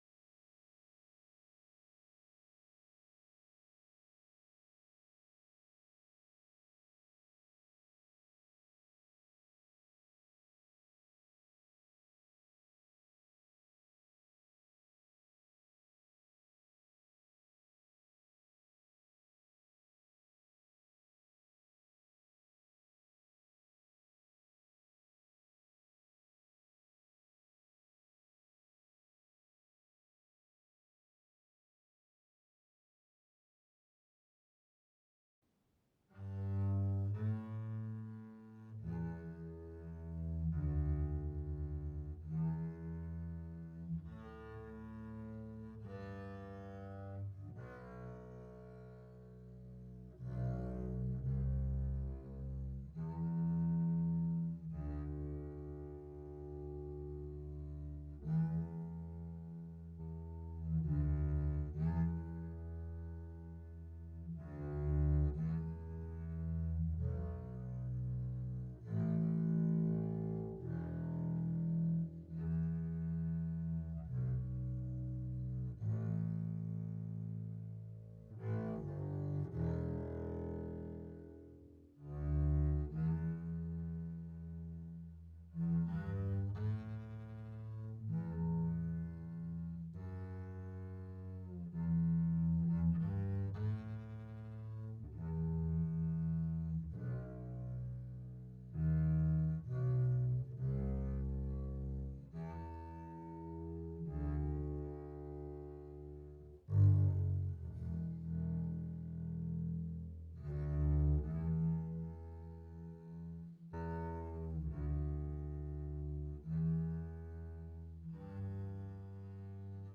Toward Tomorrow - Bass - Mono.wav